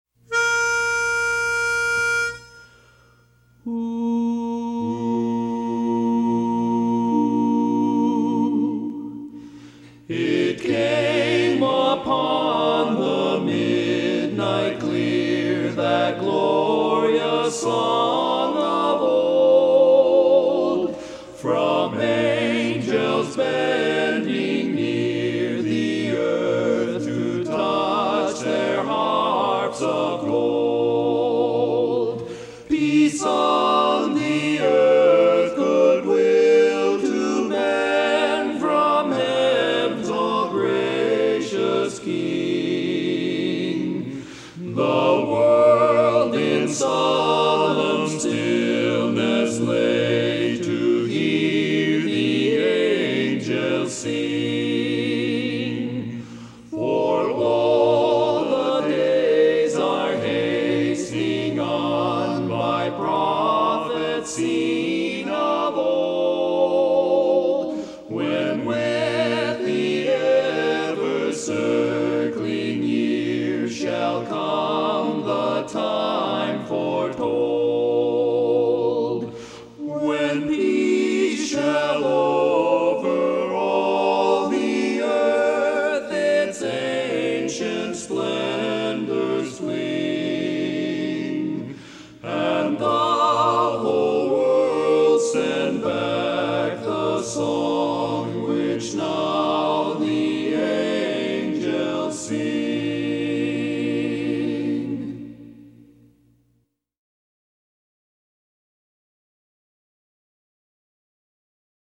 Barbershop
Bass